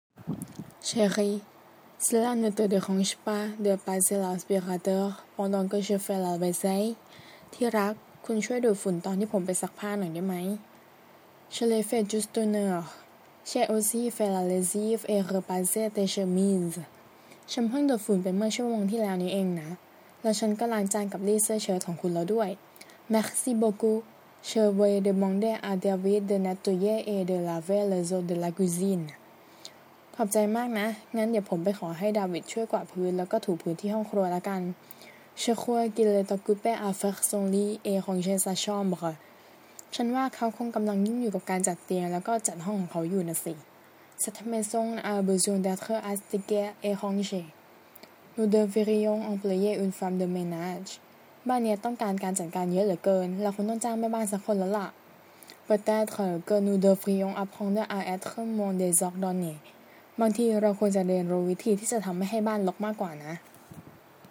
บทสนทนา12